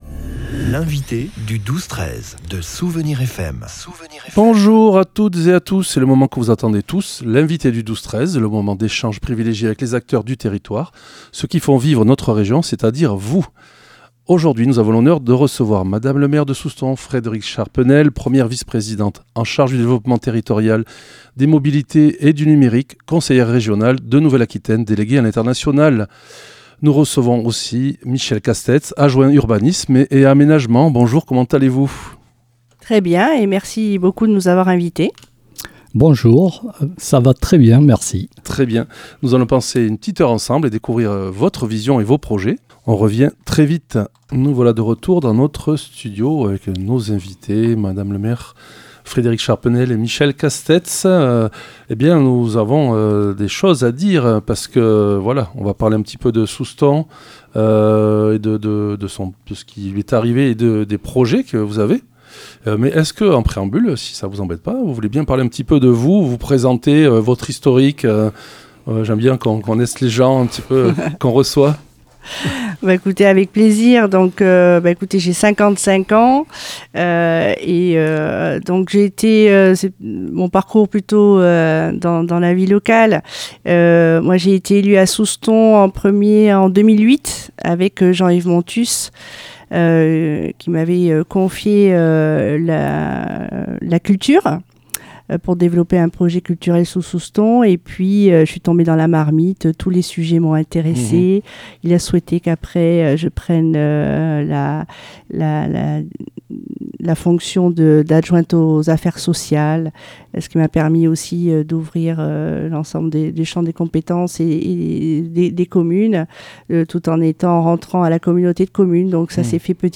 Nous avons reçu aujourd'hui lundi, Frédérique Charpenel, maire de la ville de Soustons accompagnée par Michel Castets adjoint urbanisme et aménagement.